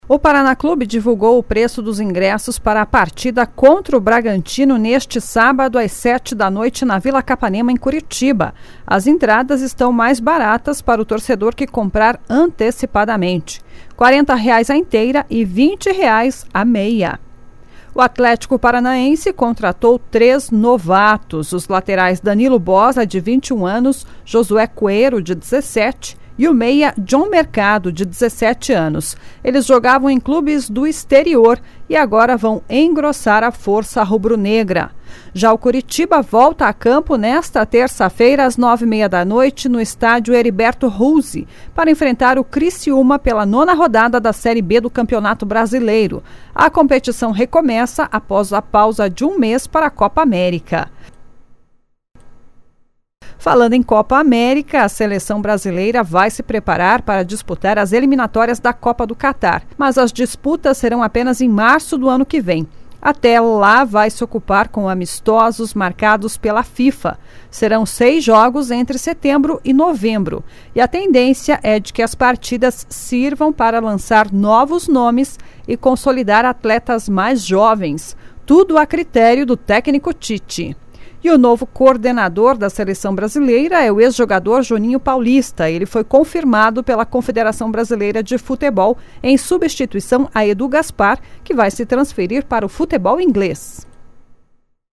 Giro Esportivo  SEM TRILHA